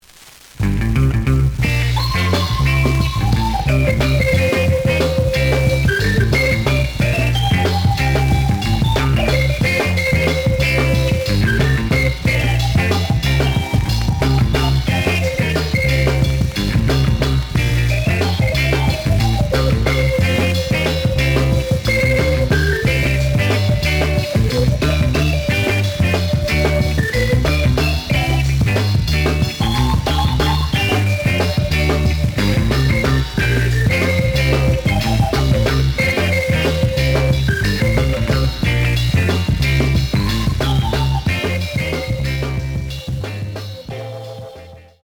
The audio sample is recorded from the actual item.
●Genre: Funk, 60's Funk
Noticeable noise on both sides.